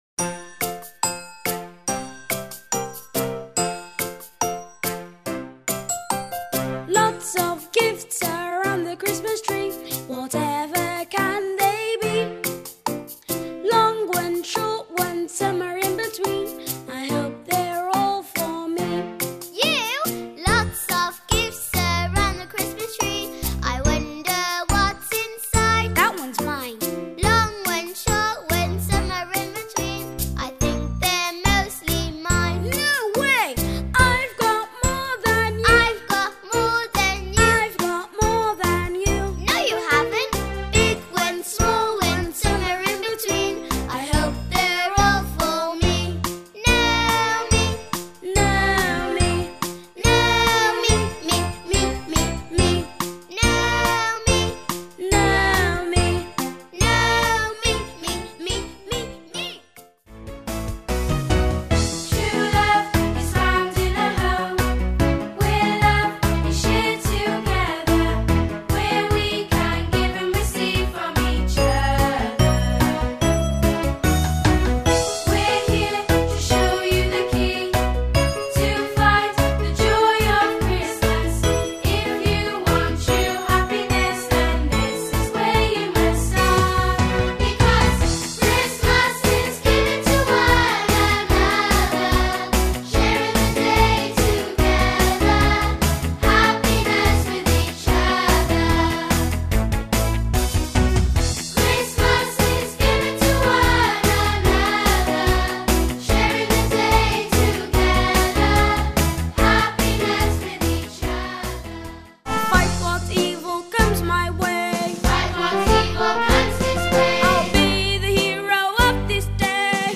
A Christmas musical